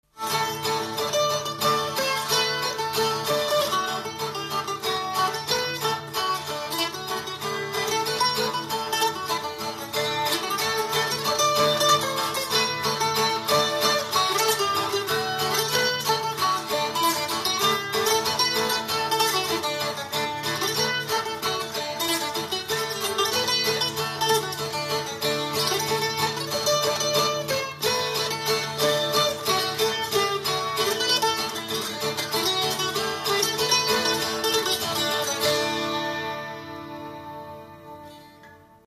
Dallampélda: Hangszeres felvétel
Alföld - Pest-Pilis-Solt-Kiskun vm. - Abony
citera Műfaj: Csárdás Gyűjtő